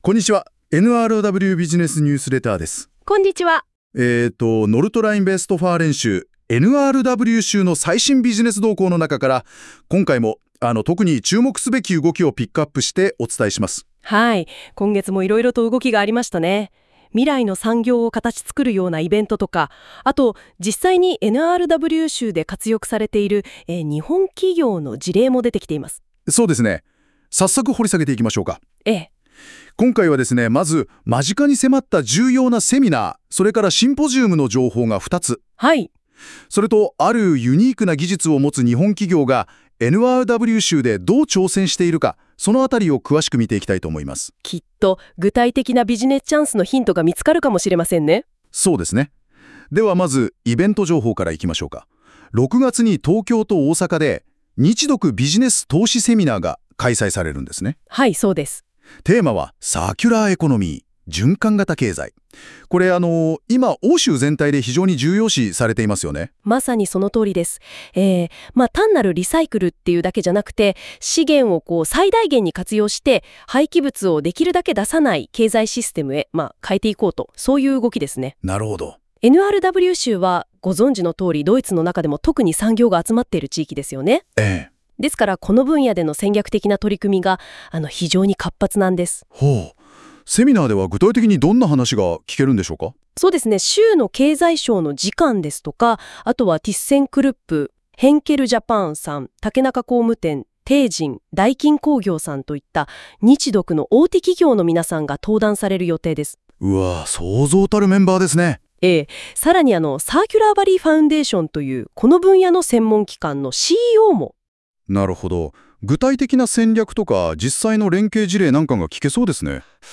生成AIを使用しているため、ベータ版という扱いだが、NRW州や私たちのニュースを音声でご提供できる新しい機会となる。